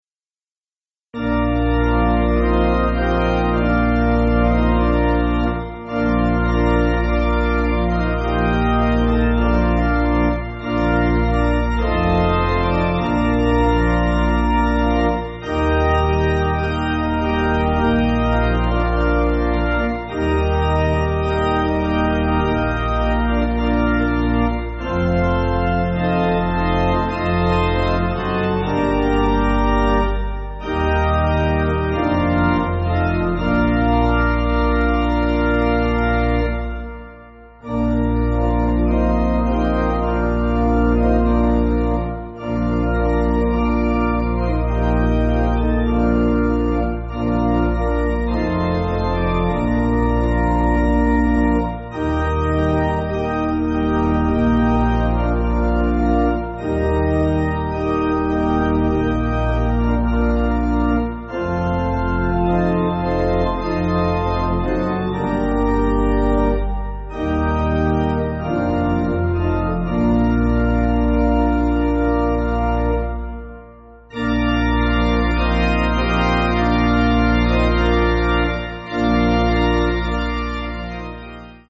Organ
(CM)   3/Bb